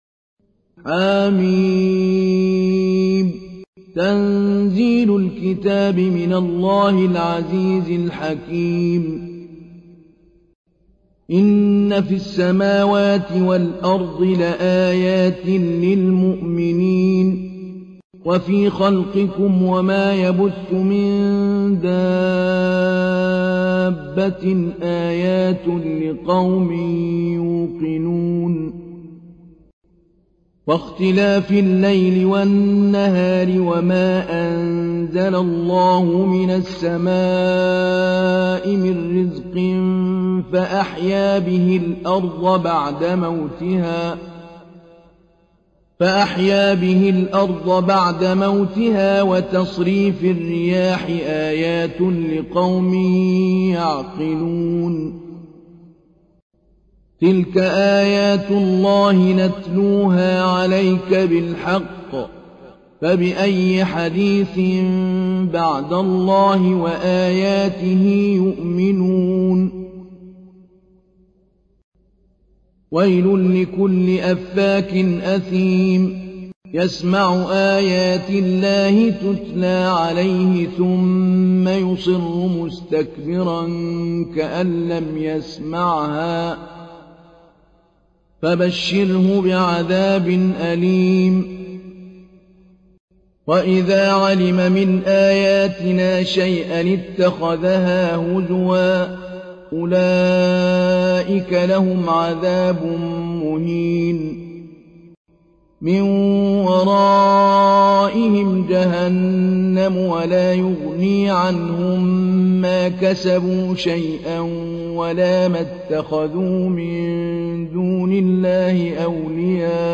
تحميل : 45. سورة الجاثية / القارئ محمود علي البنا / القرآن الكريم / موقع يا حسين